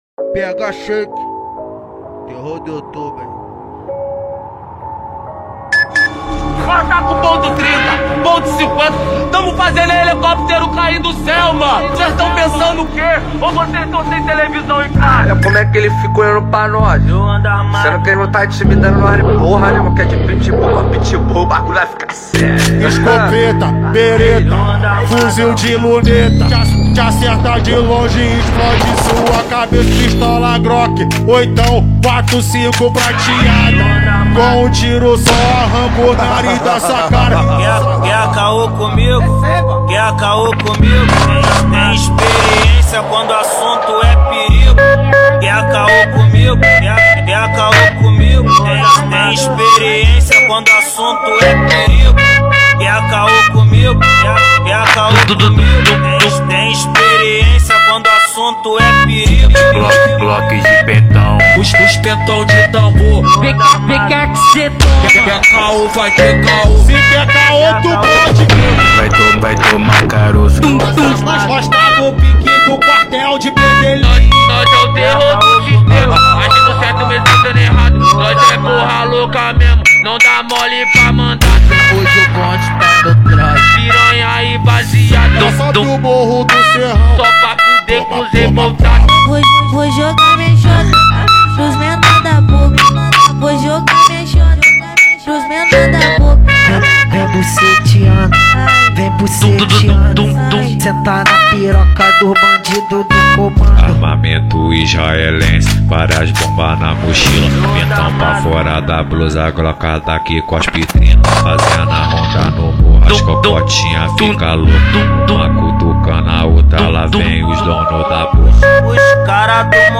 2024-04-11 15:18:16 Gênero: Funk Views